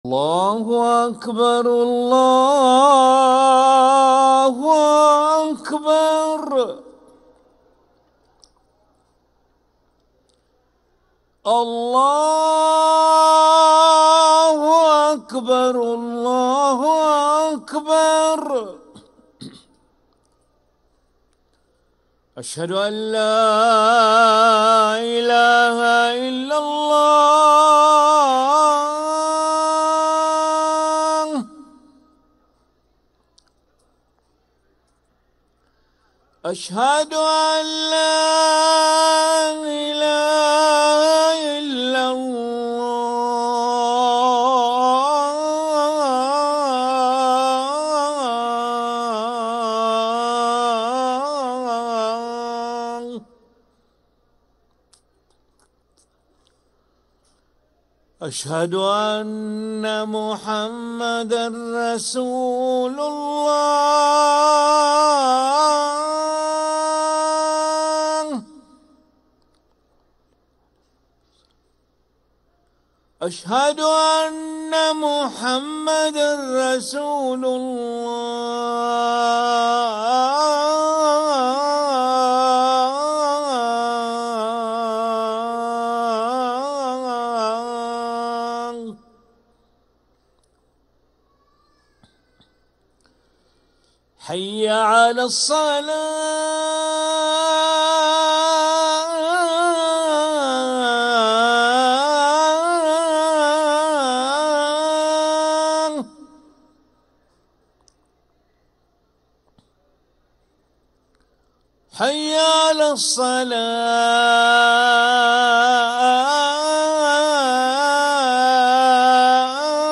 أذان العشاء للمؤذن علي ملا الخميس 16 رجب 1446هـ > ١٤٤٦ 🕋 > ركن الأذان 🕋 > المزيد - تلاوات الحرمين